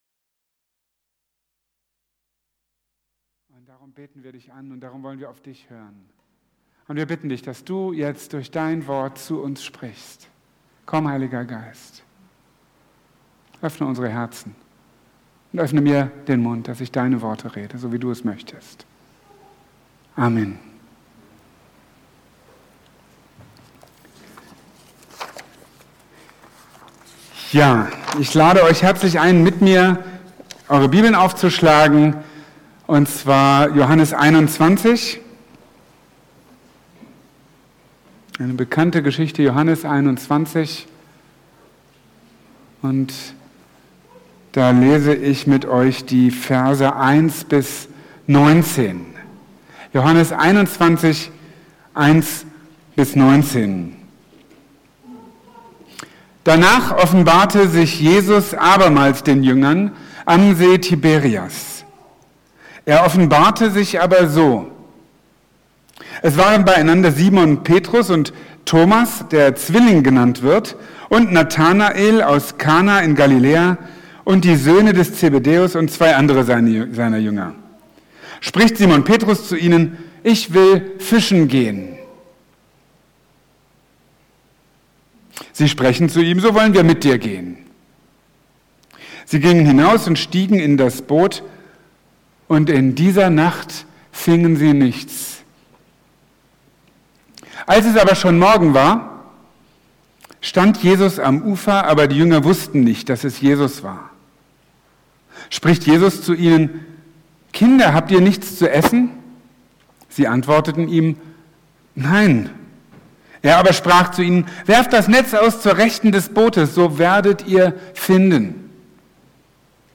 In Johannes 21,1-19 lesen wir, wie der auferstandene Jesus seinen Jüngern begegnet - liebevoll, fürsorglich, herausfordernd. In seiner Predigt vom 17.
anlässlich der Einsegnung des neuen Ältestenkreises